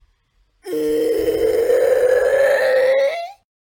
EHHHHHHHHHHHHHHHHHHHHHHHH